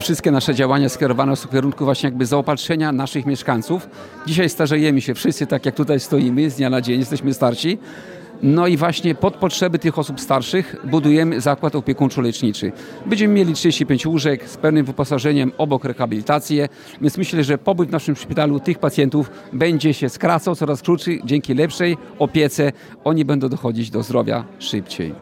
Wsparcie skierowane jest szczególnie do osób starszych mówił Tadeusz Klama – starosta kolneński.